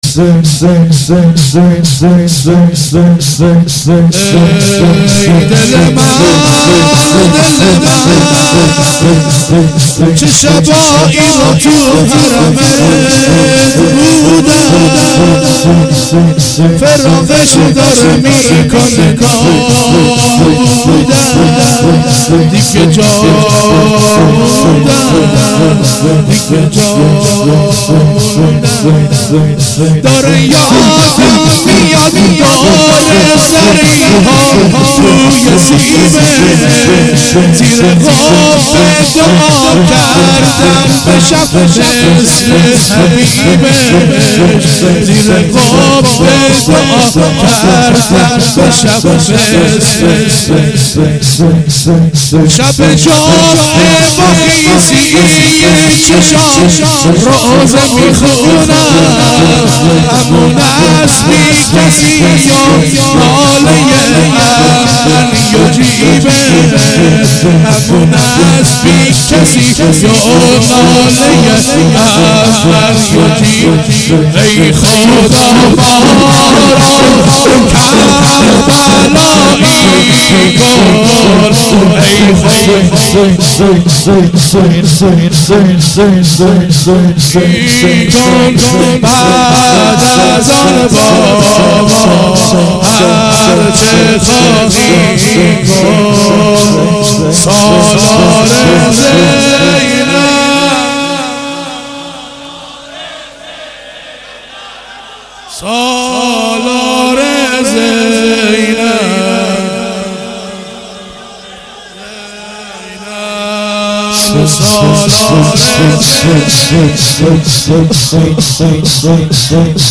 شور شب سوم محرم 96